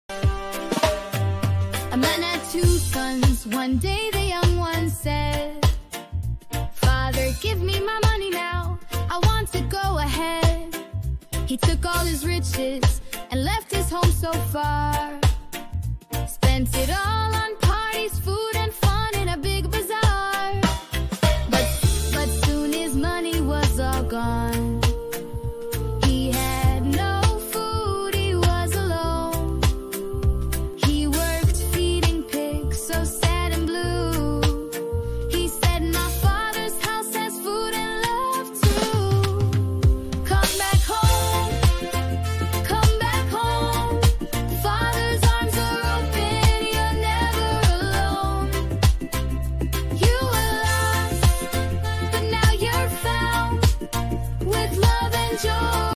A beautiful fun song on the bible story of the prodigal son.